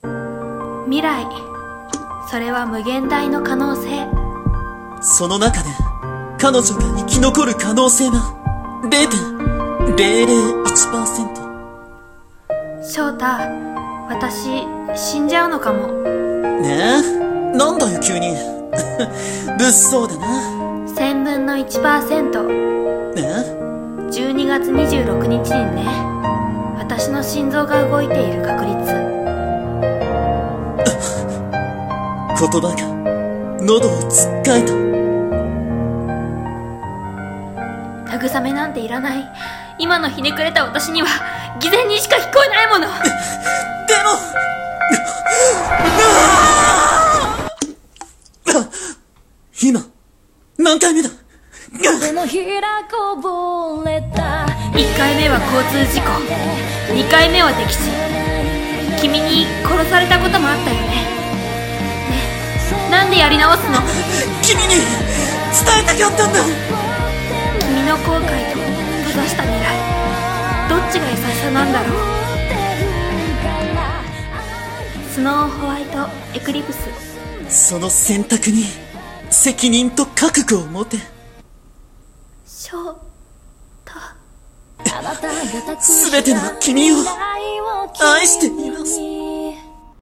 【CM声劇】スノーホワイト・エクリプス